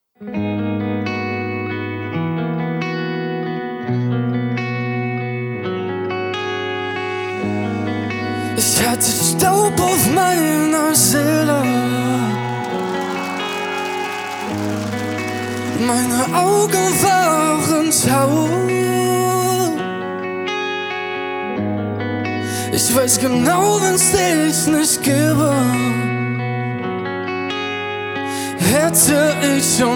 Жанр: Поп музыка
Pop, German Pop